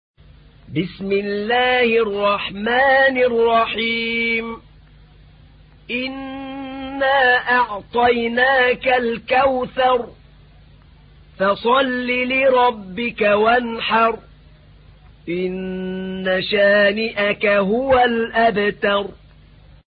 تحميل : 108. سورة الكوثر / القارئ أحمد نعينع / القرآن الكريم / موقع يا حسين